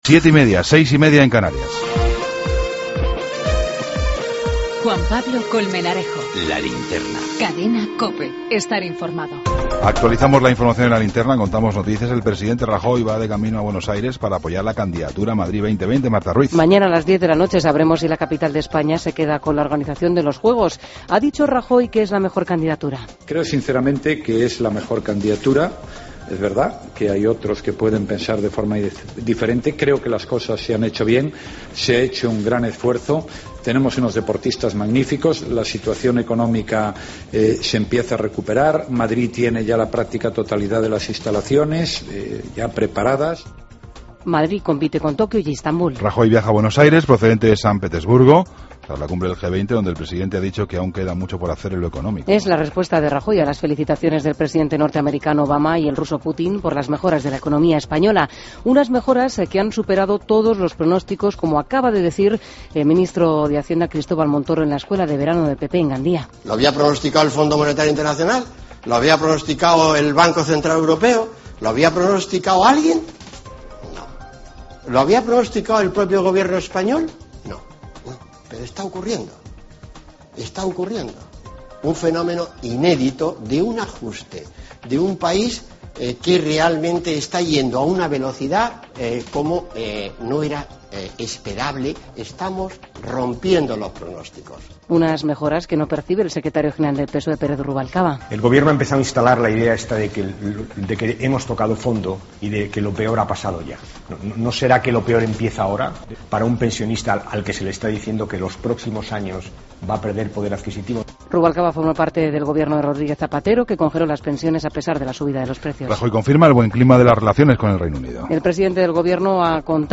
Ronda de corresponsales.
Entrevista a Ignacio González, presidente de la Comunidad de Madrid.